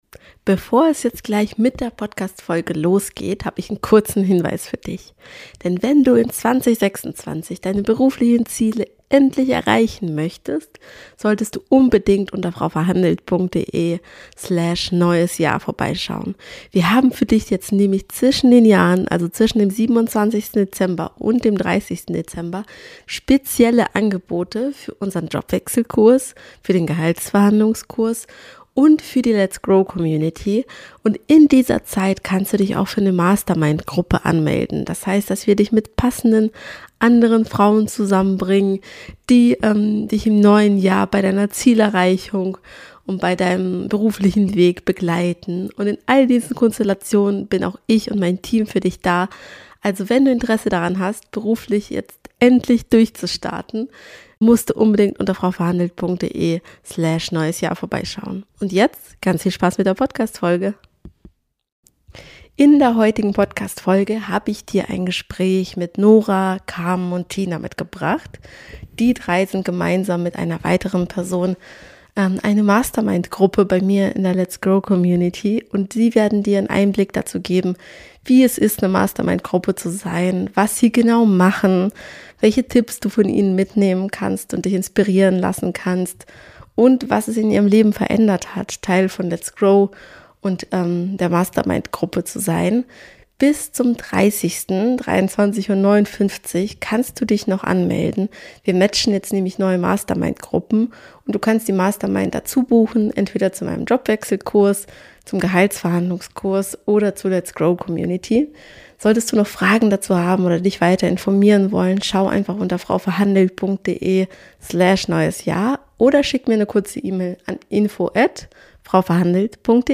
Bei diesem Interview handelt es sich um eine Wiederholung.